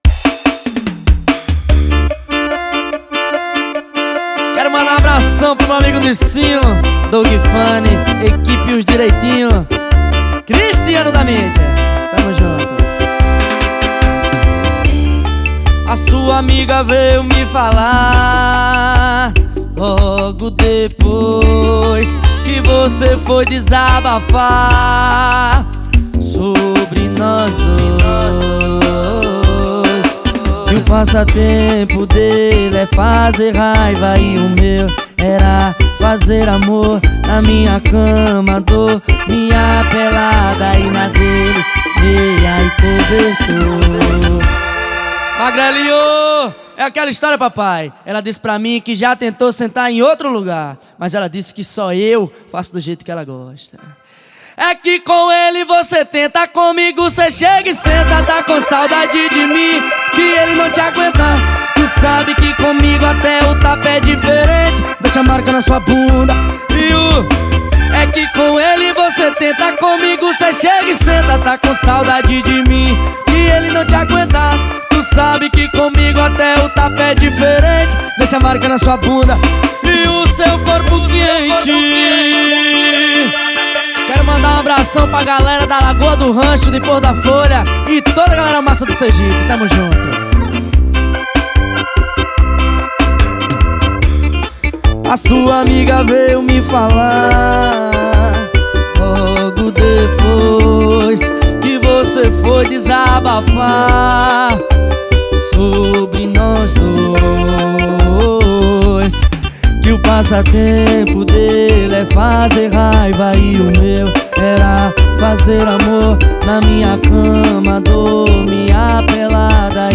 2024-11-04 16:42:43 Gênero: Arrocha Views